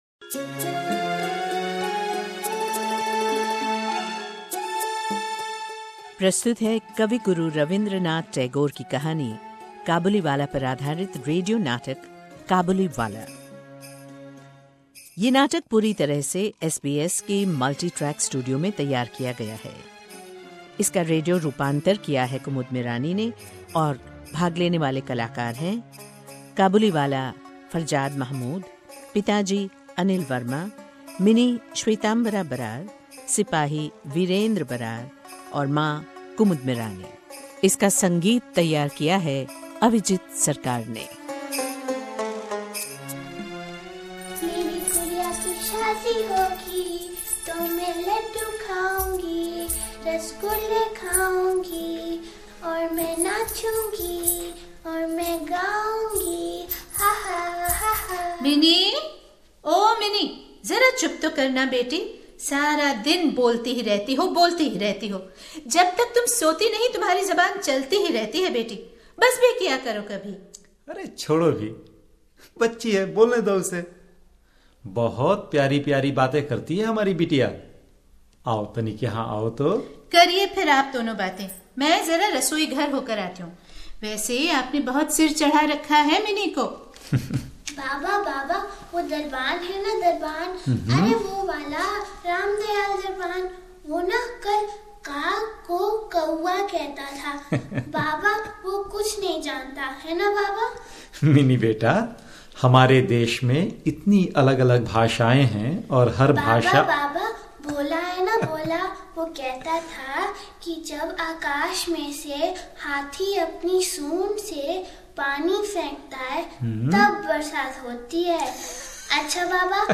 कविगुरु रविंद्रनाथ टैगोर की जन्म तिथि पर उन्हें श्रद्धांजलि अर्पित करते हुए SBS हिन्दी कार्यक्रम प्रस्तुत कर रहा है उनकी अमर कहानी काबुलीवाला पर आधारित नाटक 'काबुलीवाला". ये नाटक पूर्ण रूप से SBS मल्टीट्रैक स्टूडियो में तैयार किया गया है.